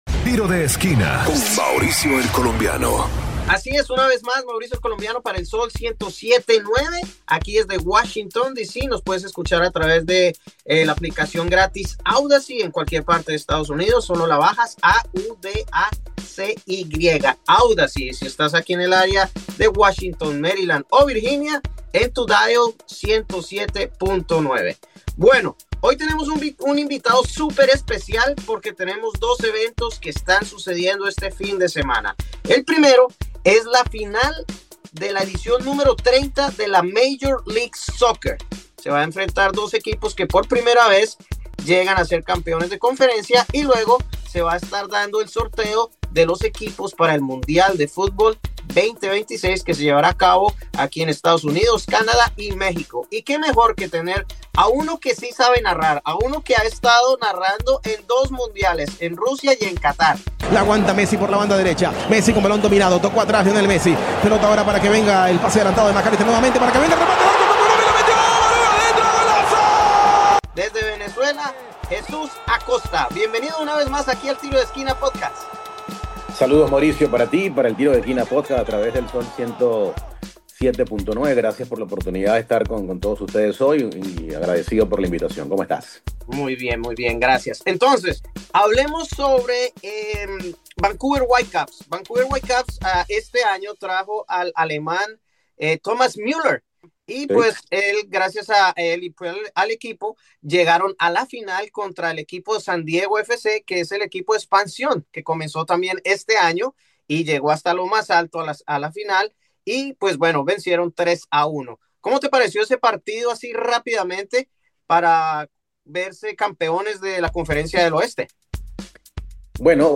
Escucha aquí el podcast y mas abajo el video de la entrevista .